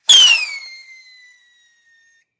rocketfly.ogg